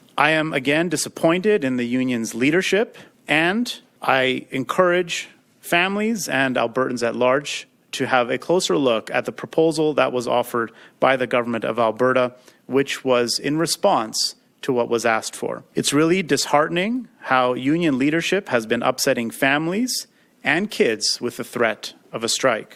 In a press conference early morning on Friday, August 29, Alberta Minister of Education Demetrios Nicolaides said he’s disheartened by the ATA’s rejection of the proposed deal.